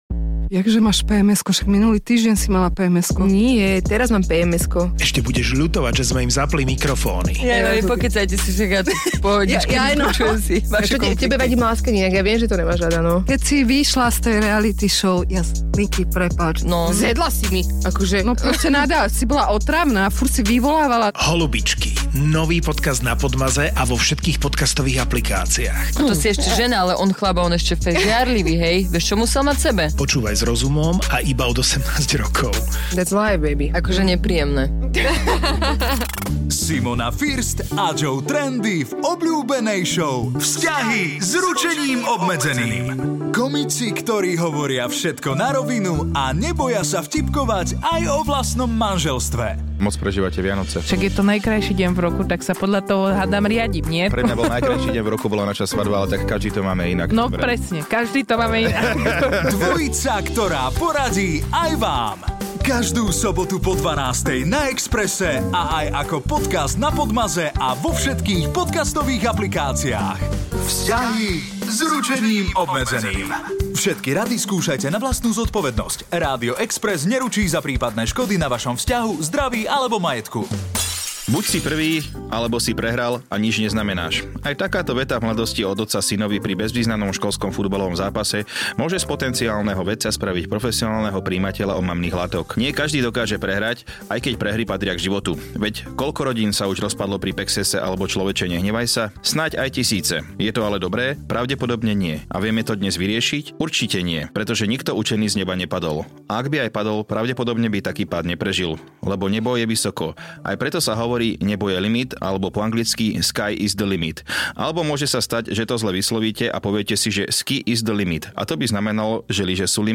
Šou VZŤAHY S RUČENÍM OBMEDZENÝM počúvajte každú sobotu po 12-tej na Exprese a aj ako podcast na Podmaze a vo všetkých podcastových aplikáciách.